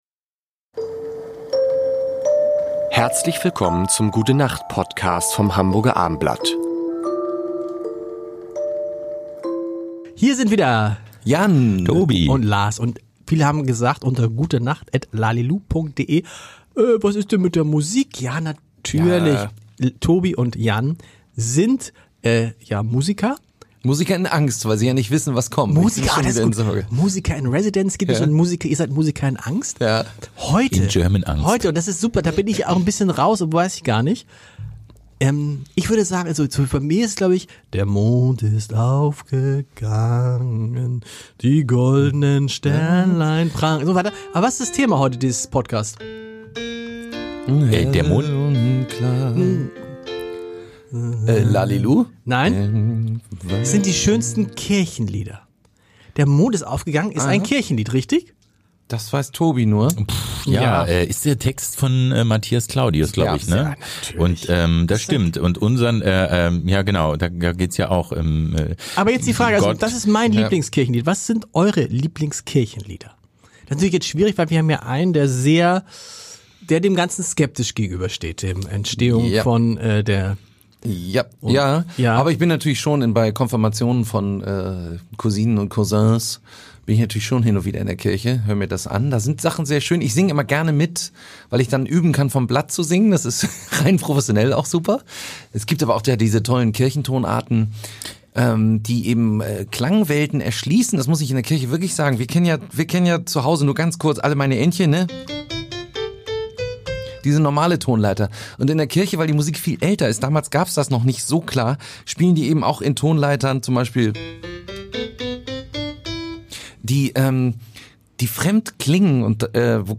Unsere Top 3 – mit Gesangsproben